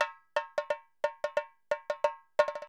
2021 Total Gabra Dholki Loops